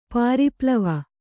♪ pāri plava